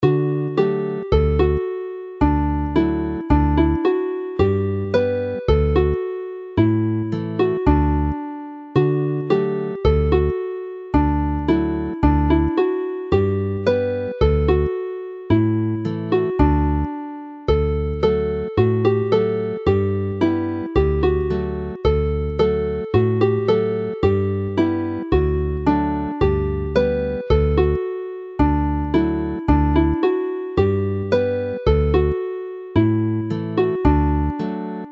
mp3 + cordiau